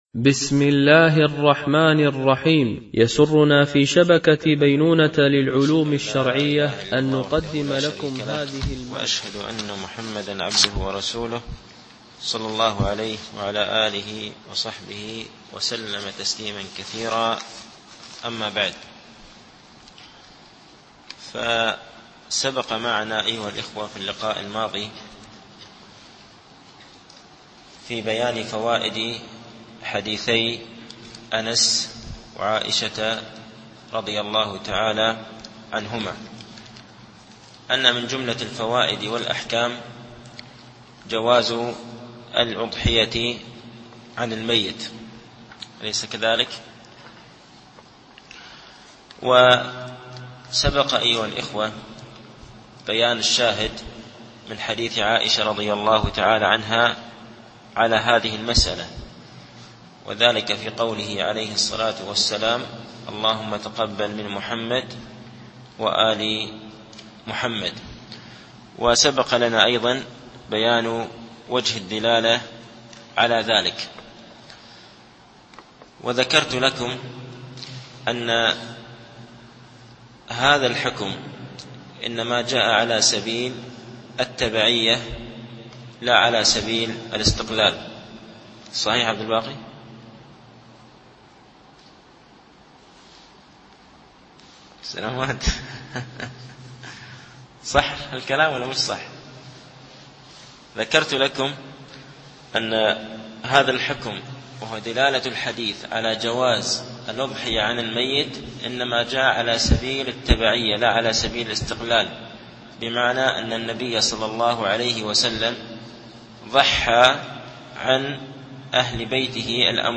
شرح كتاب الأضاحي من بلوغ المرام ـ الدرس الثاالث